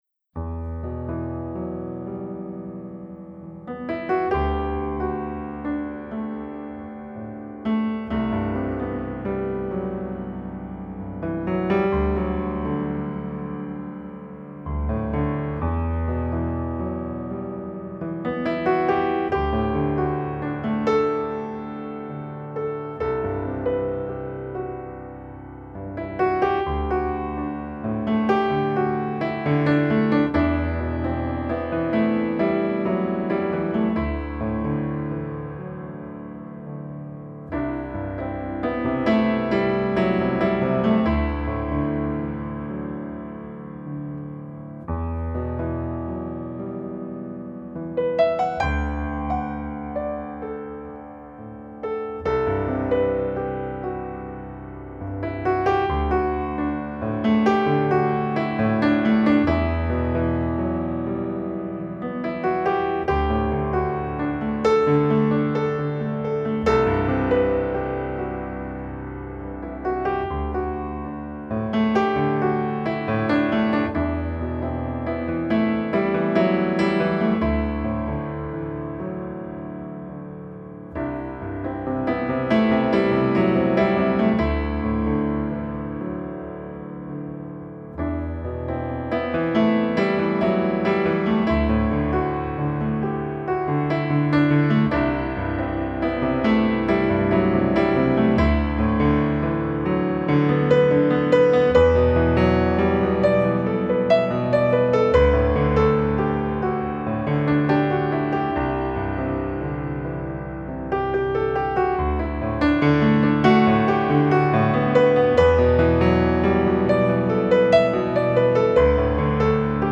موسیقی بیکلام